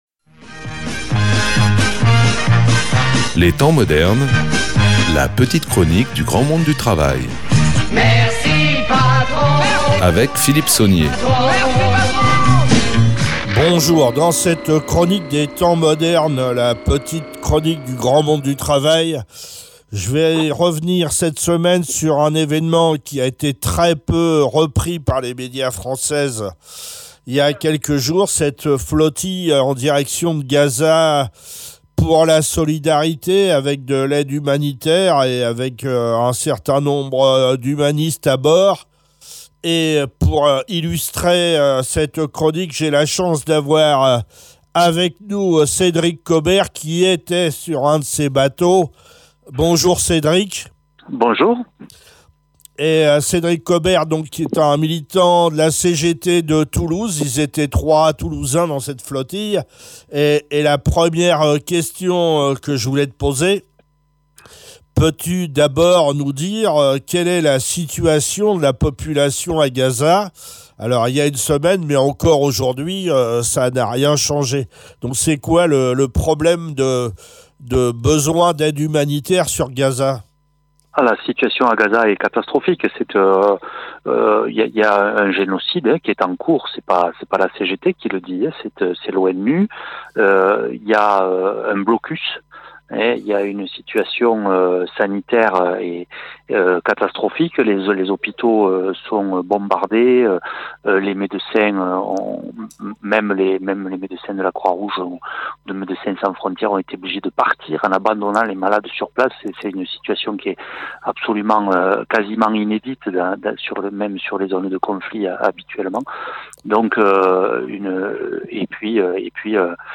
Interview de retour de la flottille pour Gaza